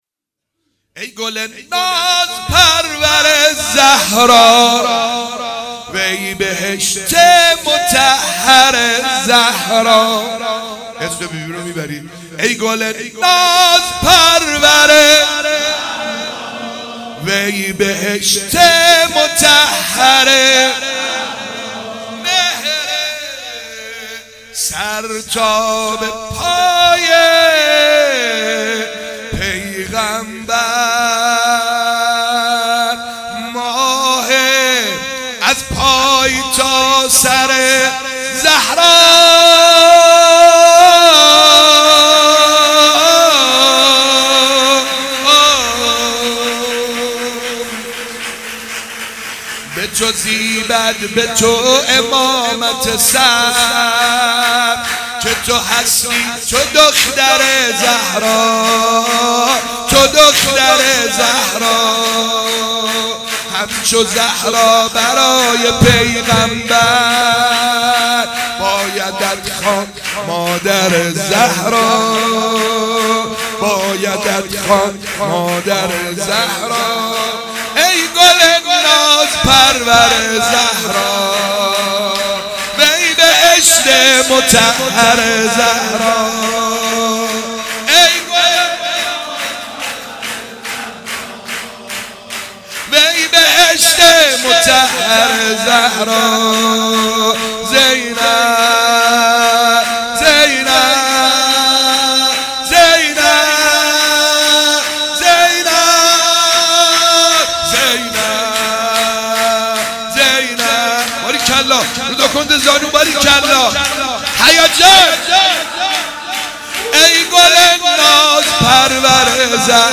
شب میلاد حضرت زینب (س) ۹۳/۱۲/۰۴ حسینیه فاطمه الزهرا(س)
با مداحی
مدح و سرود حضرت زینب (س)